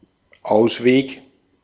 Ääntäminen
Ääntäminen France: IPA: [ʁə.kuʁ] Haettu sana löytyi näillä lähdekielillä: ranska Käännös Ääninäyte Substantiivit 1.